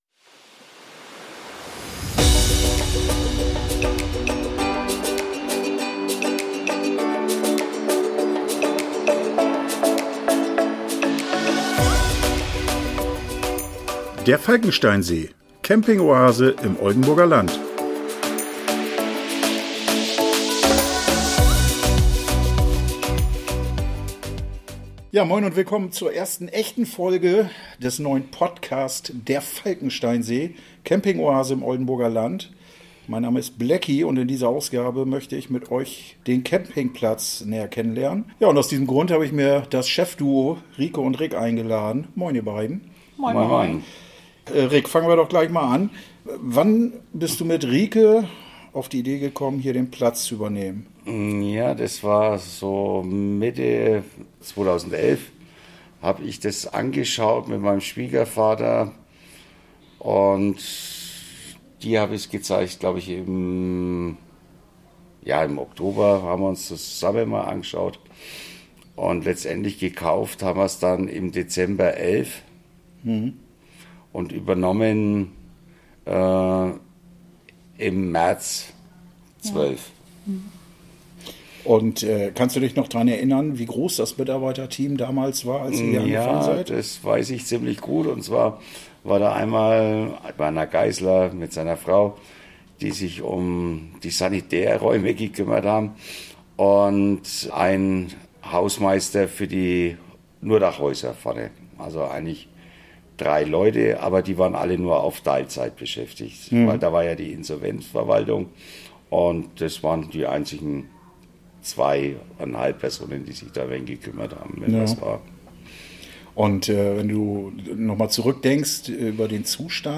bei mir am Mikro und wir plaudern etwas über die Anfänge, die Herausforderungen und die schönen Seiten.